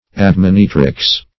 admonitrix - definition of admonitrix - synonyms, pronunciation, spelling from Free Dictionary Search Result for " admonitrix" : The Collaborative International Dictionary of English v.0.48: Admonitrix \Ad*mon"i*trix\, n. [L.] A female admonitor.